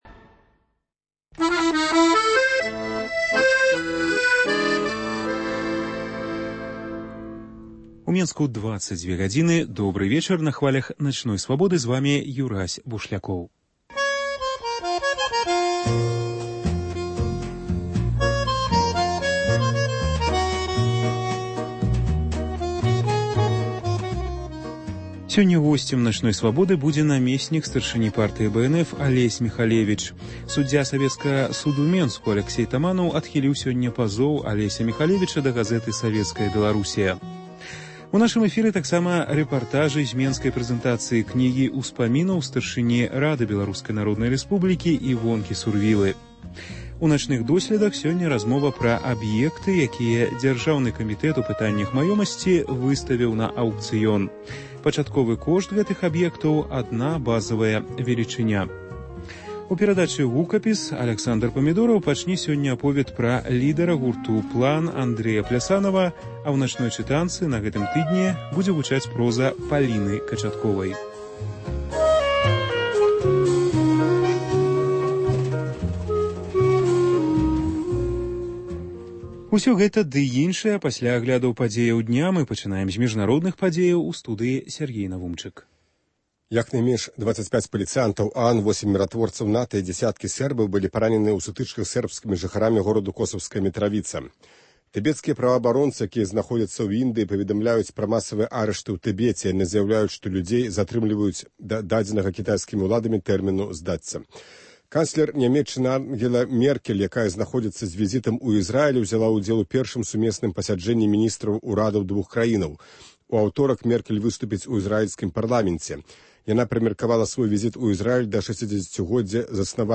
Вечаровы госьць – намесьнік старшыні Партыі БНФ Алесь Міхалевіч. У “Начных досьледах” – размова пра аб’екты, якія Дзяржаўны камітэт у пытаньнях маёмасьці выставіў на аўкцыён. Рэпартаж зь менскай прэзэнтацыі кнігі ўспамінаў старшыні Рады БНР Івонкі Сурвілы “Дарога”.